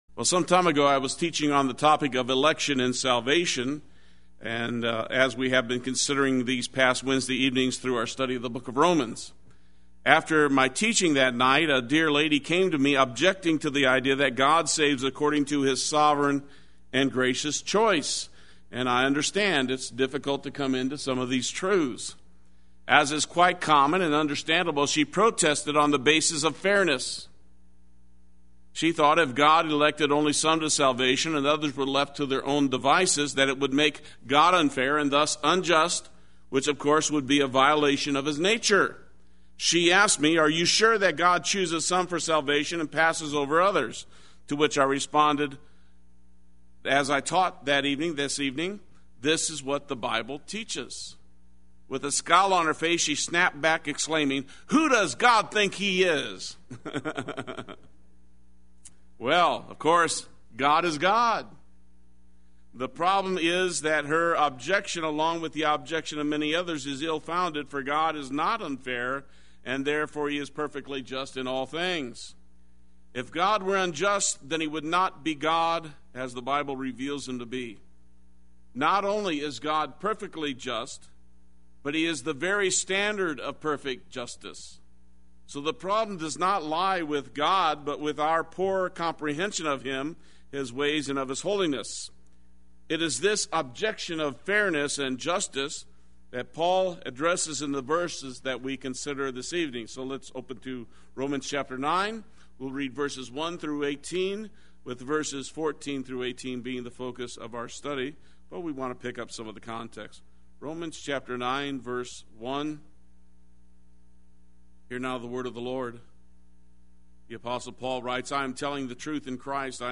Play Sermon Get HCF Teaching Automatically.
God Who Has Mercy Wednesday Worship